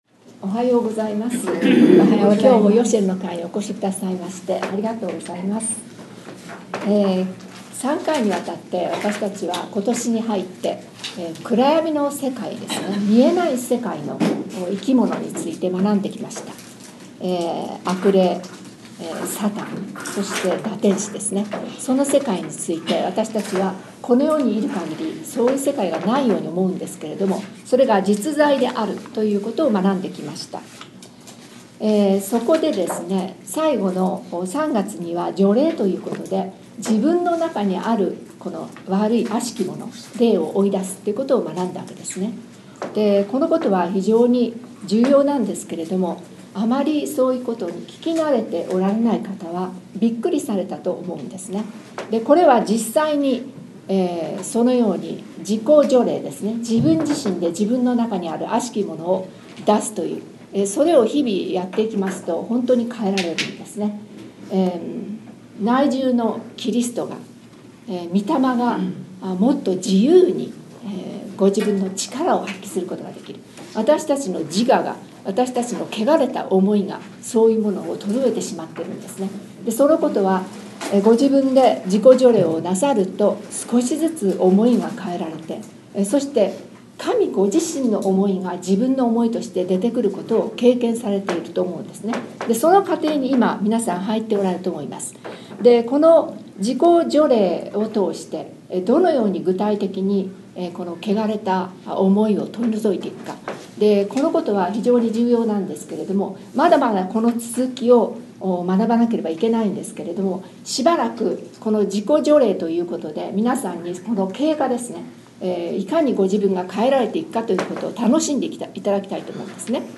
4月11日に第28回ヨシェルの会が開催されました。参加者は20名でした。